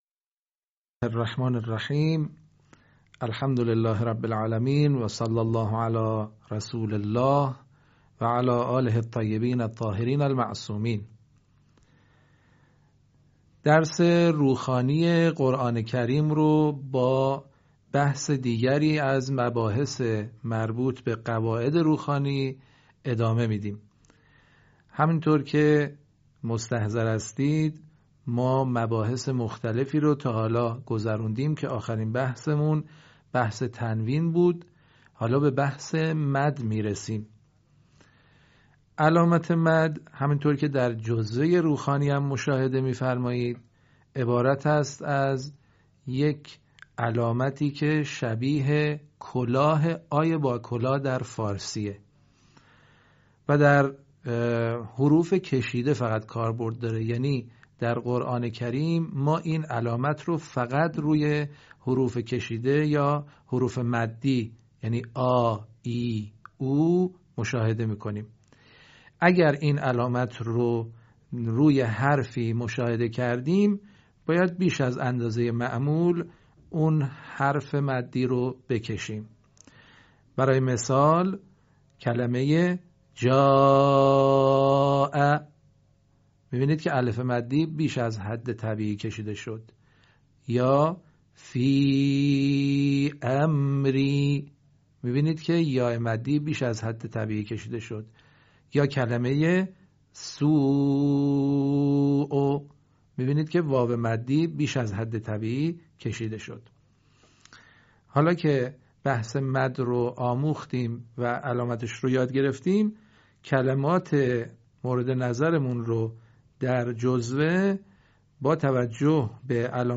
صوت | آموزش مد و حروف مدی در روخوانی و روانخوانی قرآن